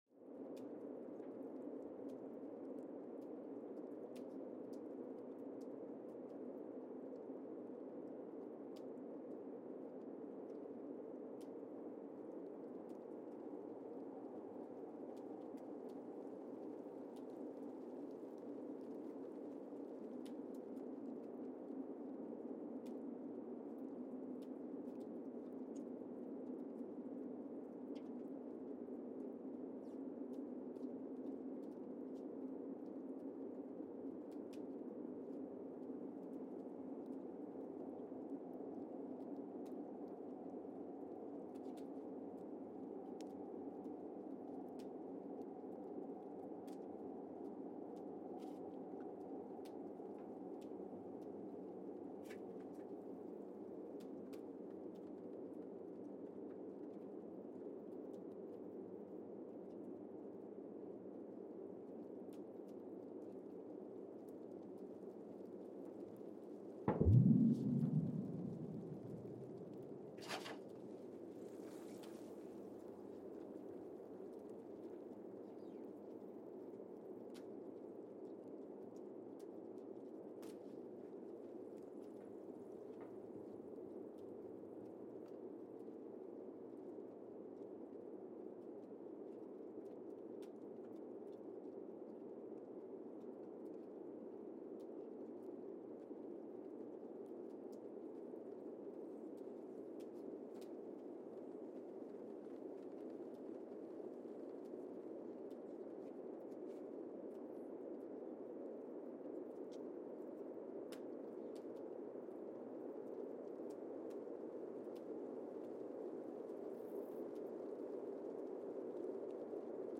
Casey, Antarctica (seismic) archived on May 2, 2025
Station : CASY (network: GSN) at Casey, Antarctica
Sensor : Streckheisen STS-1VBB
Speedup : ×1,800 (transposed up about 11 octaves)
Loop duration (audio) : 05:36 (stereo)
Gain correction : 25dB
SoX post-processing : highpass -2 90 highpass -2 90